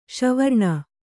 ♪ ṣa varṇa